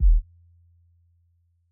DB - Kick (21).wav